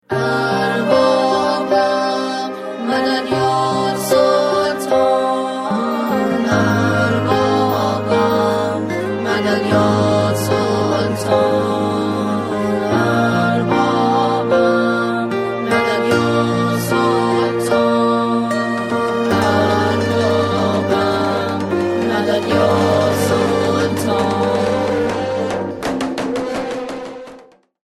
زنگ موبایل
رینگتون احساسی و باکلام